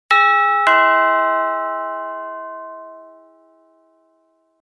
Doorbell